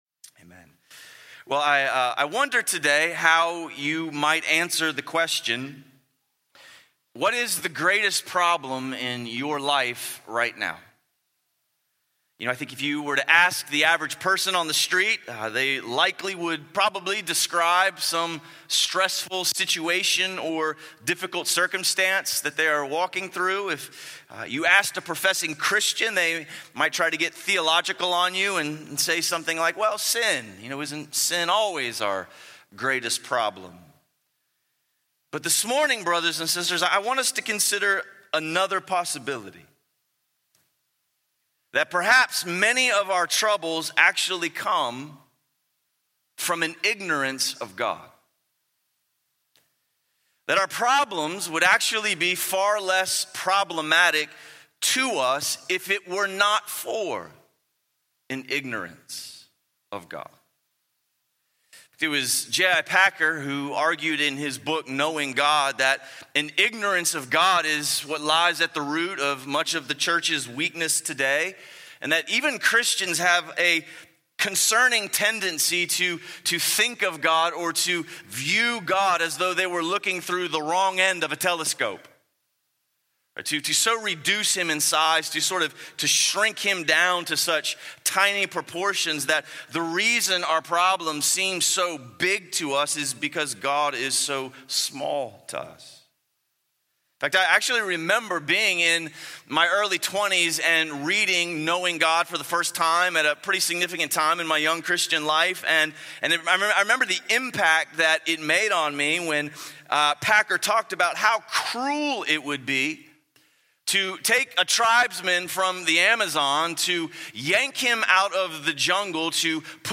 Sunday Sermons – Crossway Community Church